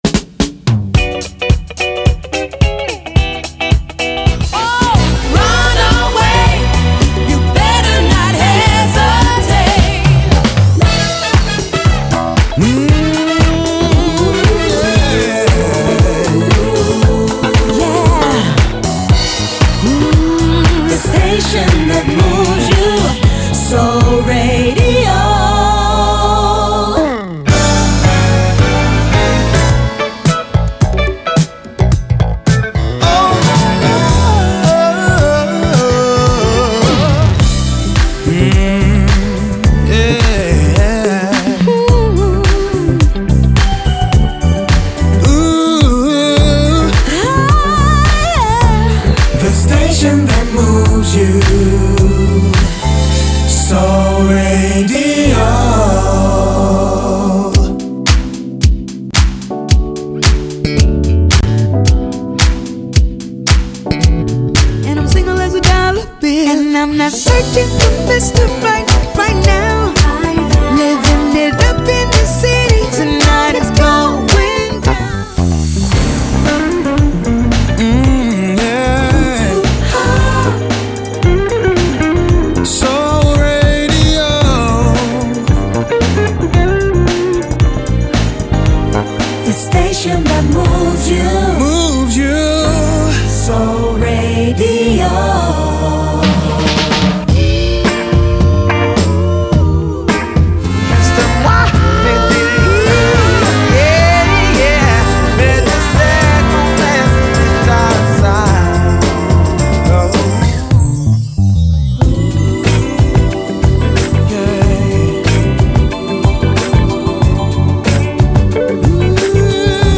jingle package